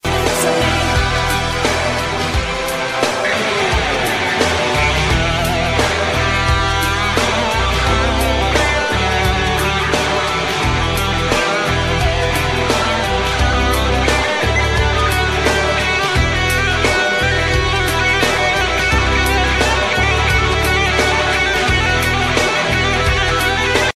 Jazz Ringtones